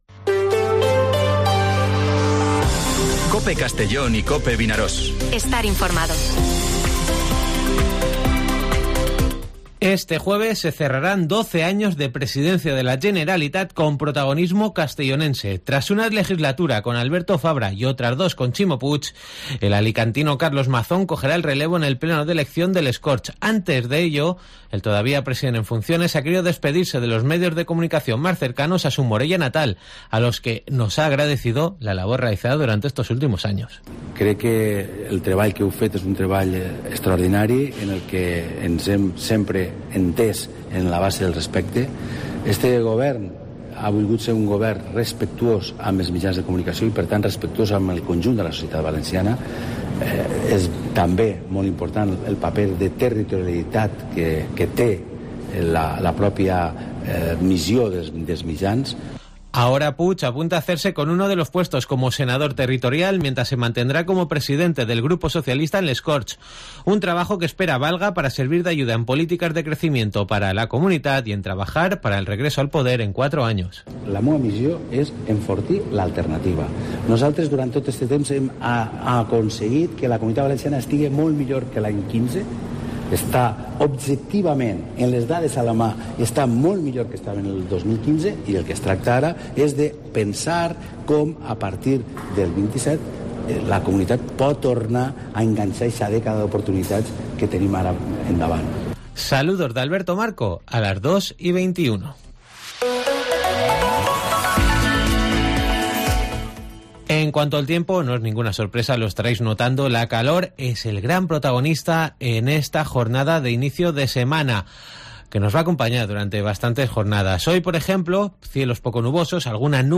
Informativo Mediodía COPE en Castellón (10/07/2023)